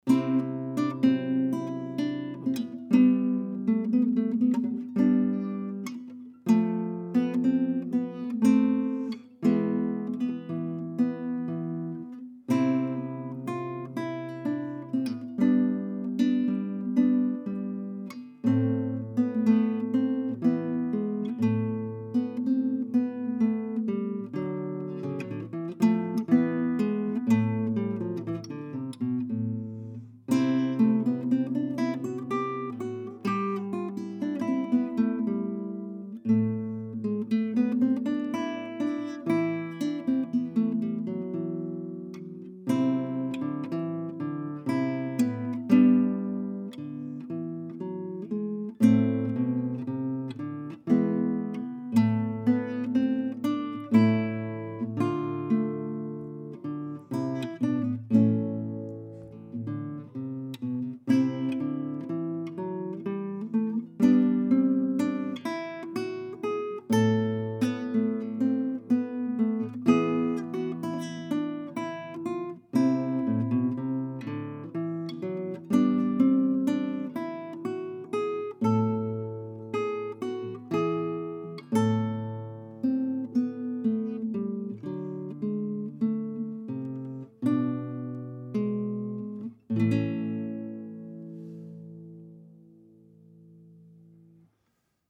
CONCERT RADIOPHONIQUE A L’AUDITORIUM XENAKIS DE STAINS
En partenariat avec le CMMD de Stains, émission enregistrée en public à l’auditorium Xénakis.
guitare